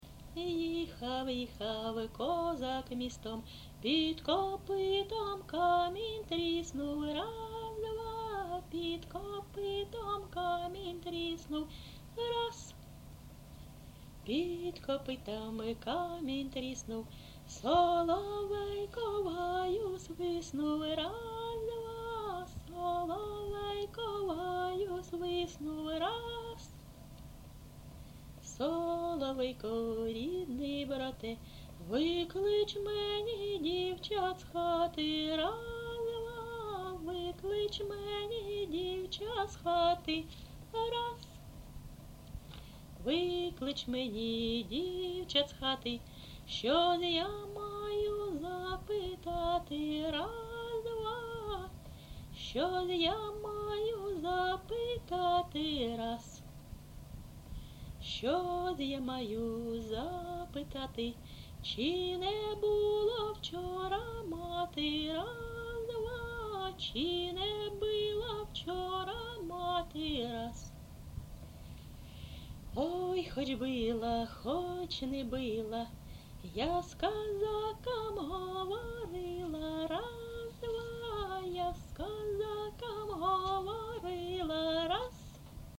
ЖанрПісні з особистого та родинного життя, Козацькі
Місце записум. Ровеньки, Ровеньківський район, Луганська обл., Україна, Слобожанщина